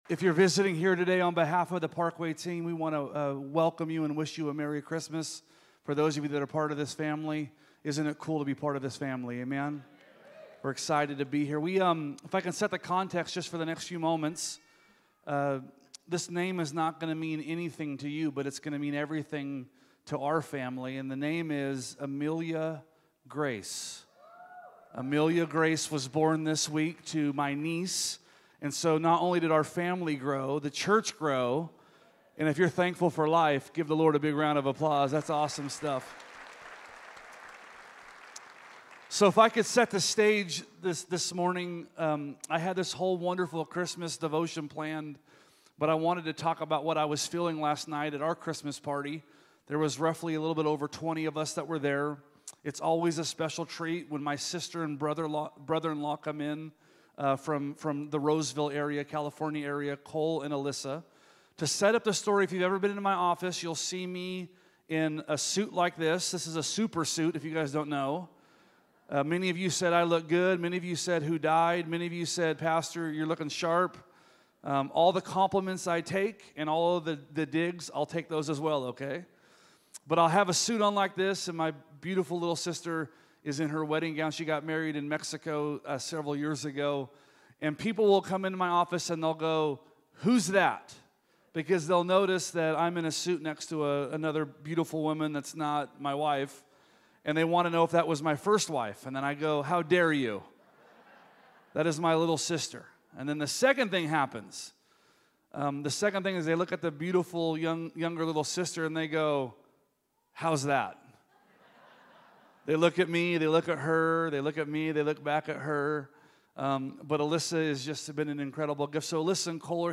Sermons | Parkway Christian Center